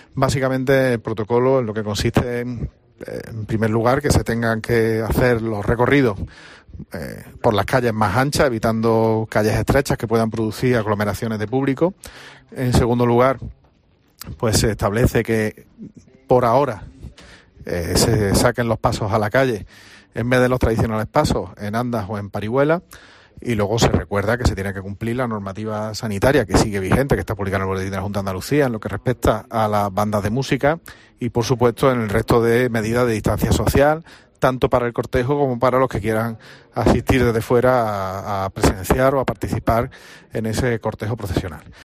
José María Bellido, alcalde de Córdoba